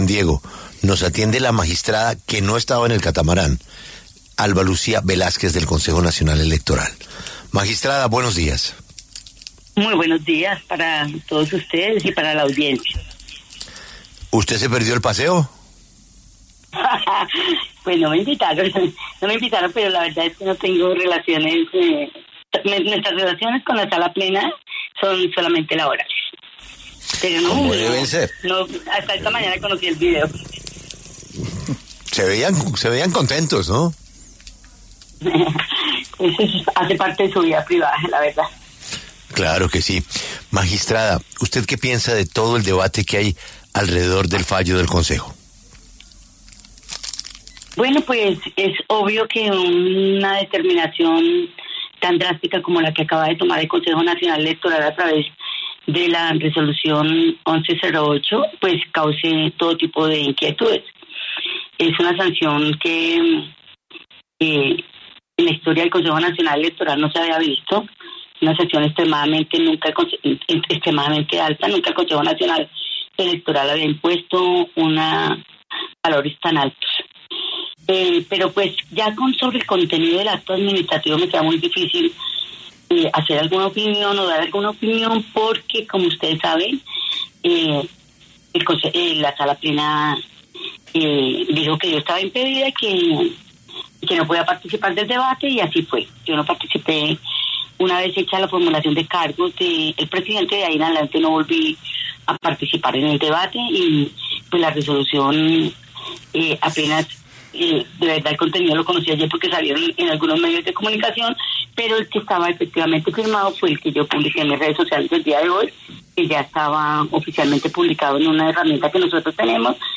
Velásquez pasó por los micrófonos de La W y dijo que la sanción es inédita y que en caso similares no se ha fallado en ese sentido.